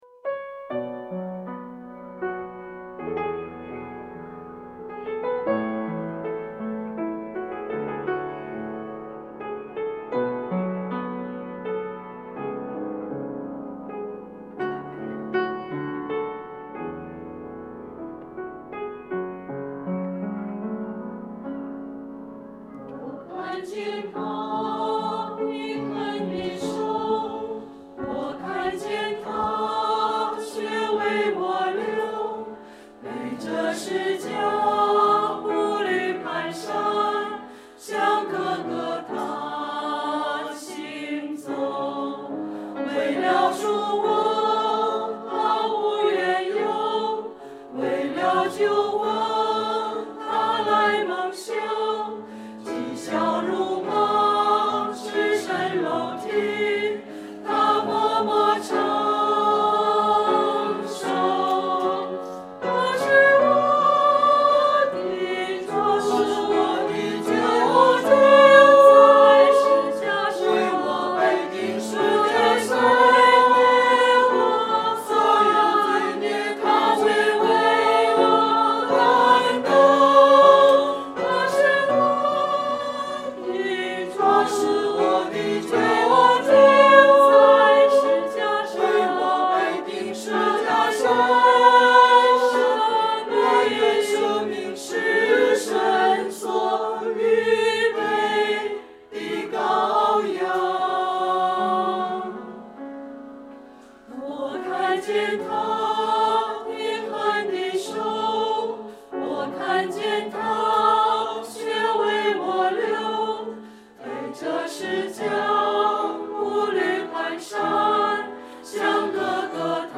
复活节证道：只在今生有指望？